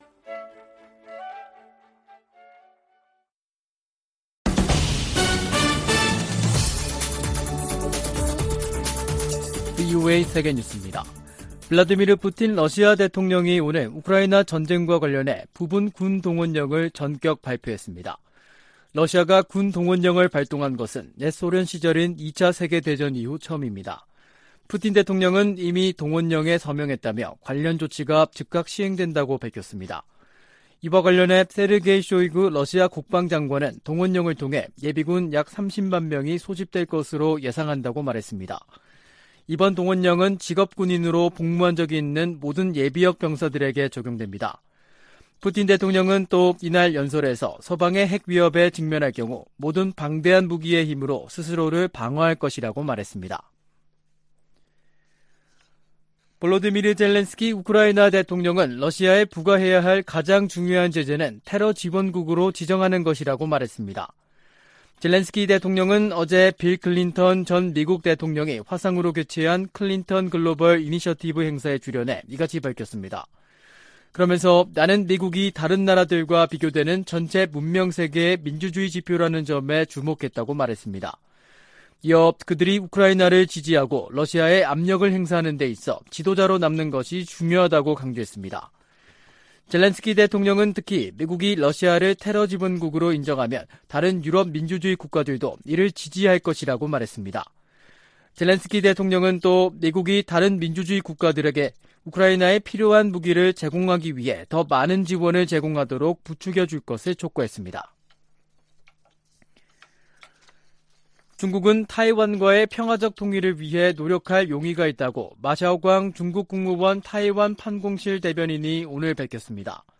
VOA 한국어 간판 뉴스 프로그램 '뉴스 투데이', 2022년 9월 21일 3부 방송입니다. 조 바이든 미국 대통령이 유엔총회 연설에서 유엔 안보리 개혁의 필요성을 강조할 것이라고 백악관이 밝혔습니다. 윤석열 한국 대통령은 유엔총회 연설에서 자유를 지켜야 한다고 역설했습니다. 미국 정부가 북한인권특사 인선을 조만간 발표할 것으로 기대한다고 성 김 대북특별대표가 말했습니다.